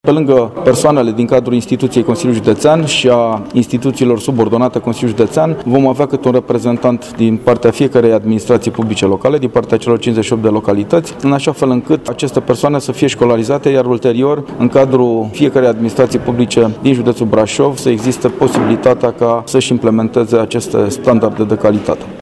Aplicarea acestor instrumente de management al calității va determina creșterea transparenței, eticii și integrității personalului din administrația publică, în toate unităţile administrativ teritoriale din judeţ, după cum ne-a explicat Adrian Veştea, preşedintele Consiliului Judeţean Braşov: